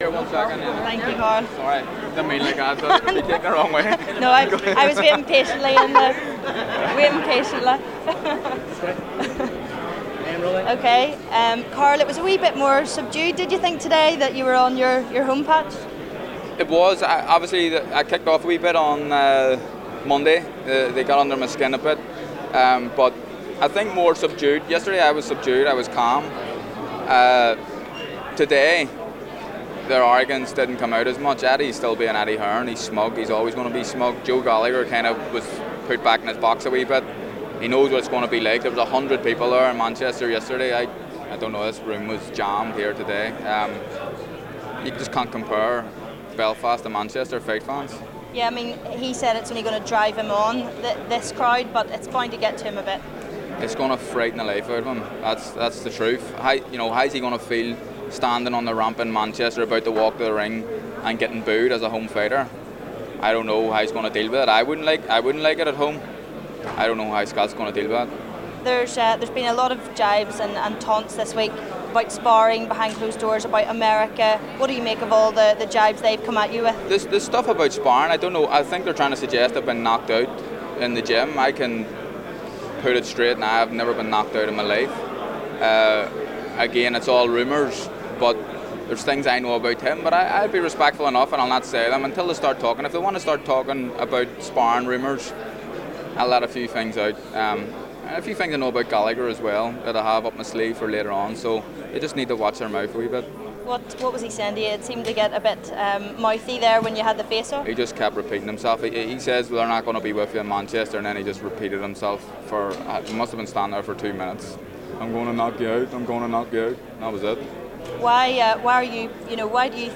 Carl Frampton speaks to U105 Sport ahead of his title bout with Scott Quigg